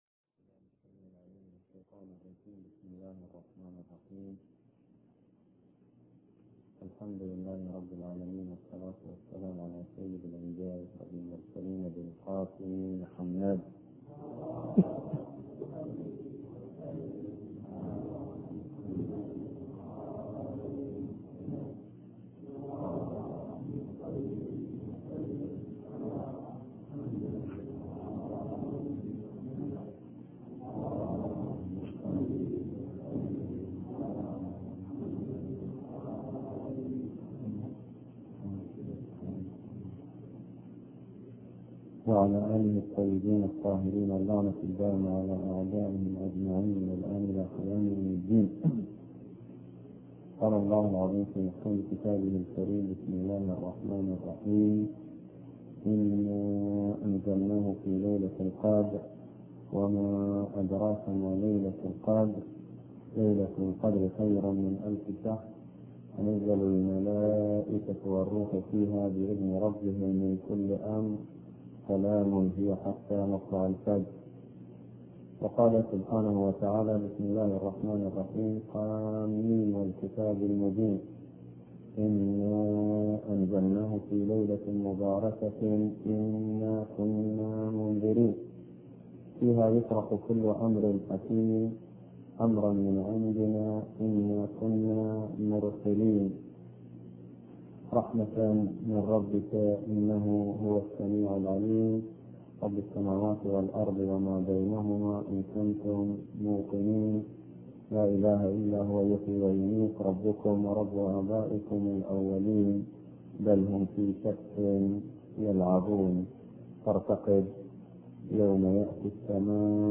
ملف صوتی التخطيط لليلة القدر بصوت الشيخ نمر باقر النمر
كلمة الجمعة